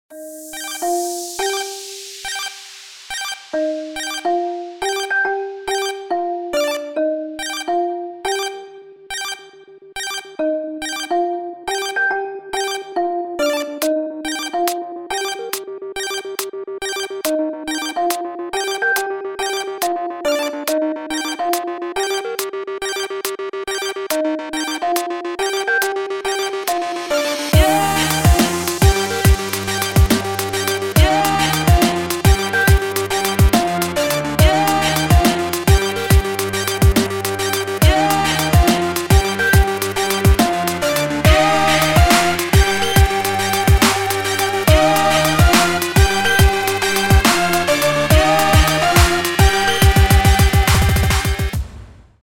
• Качество: 256, Stereo
мужской голос
громкие
dance
Electronic
EDM
электронная музыка
спокойные
клавишные
Dubstep
ксилофон